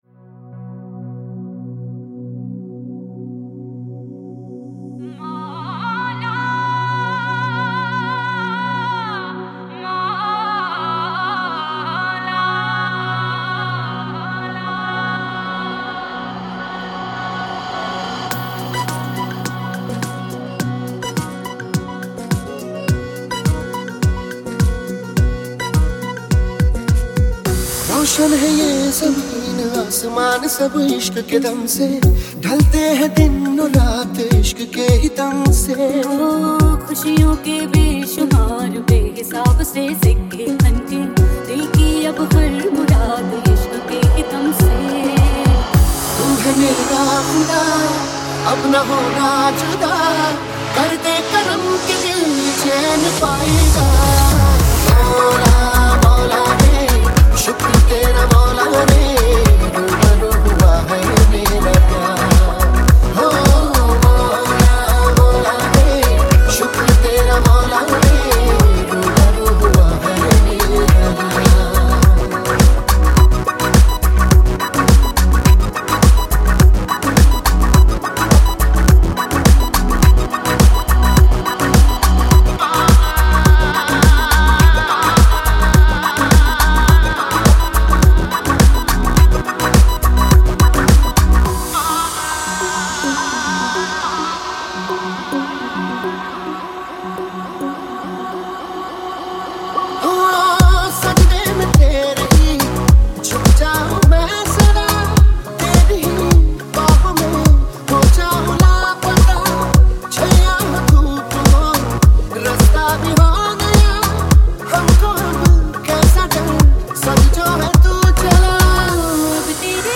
.mp3 Song Download Bollywood Mazafree